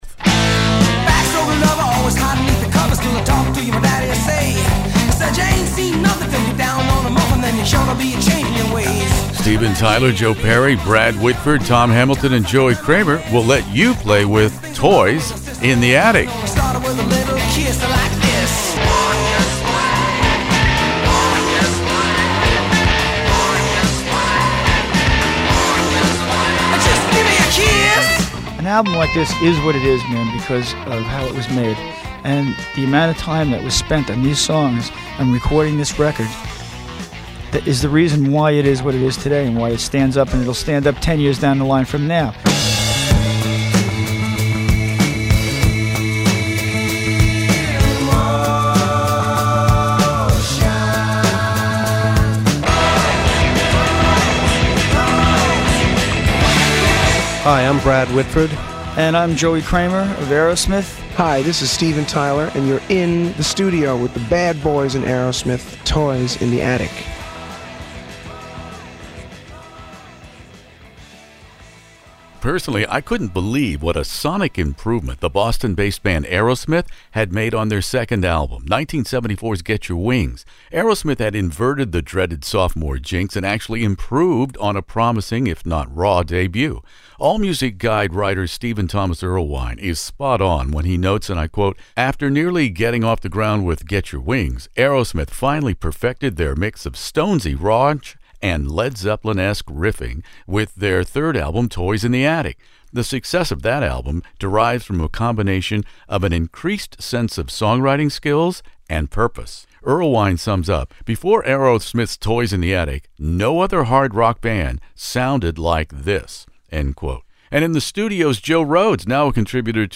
Aerosmith "Toys in the Attic" interview with Steven Tyler, Brad Whitford